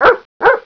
Bark
bark.wav